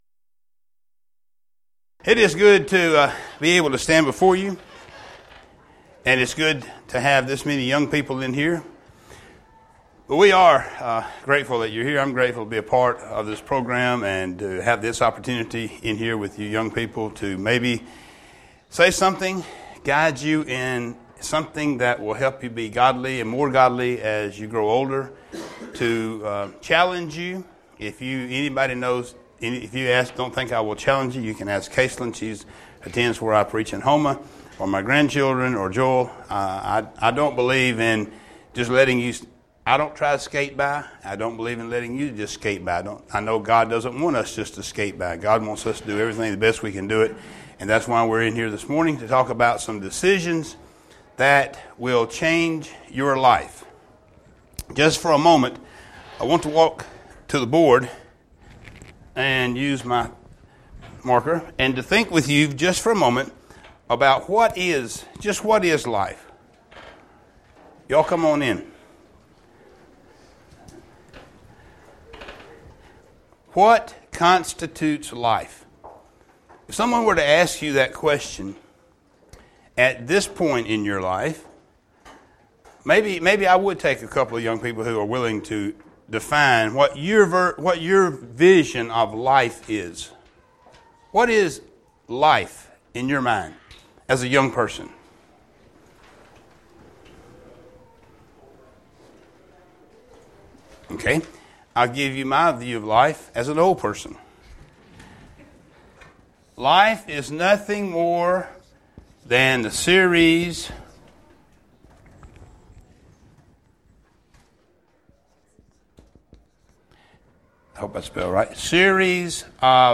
Event: 2014 Focal Point Theme/Title: Preacher's Workshop
Youth Sessions